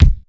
Kick_23.wav